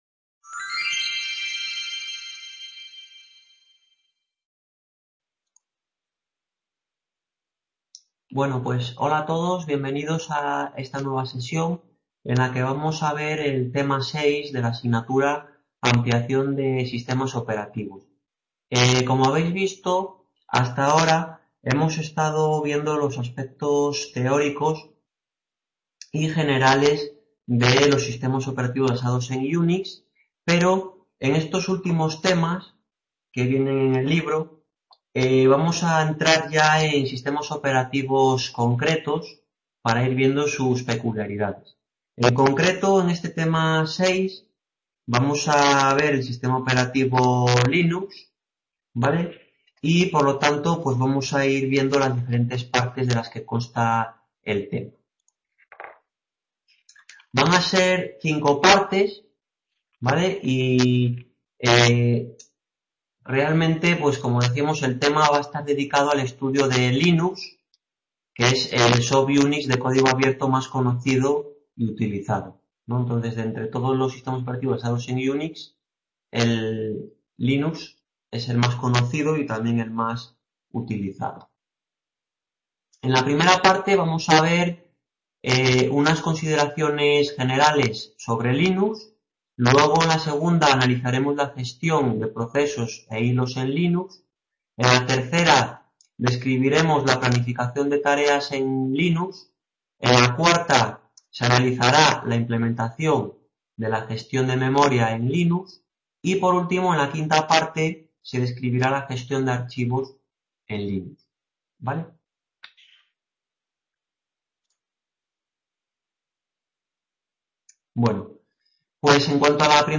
Tutoría Intercampus 10 - AMPLIACIÓN DE SISTEMAS OPERATIVOS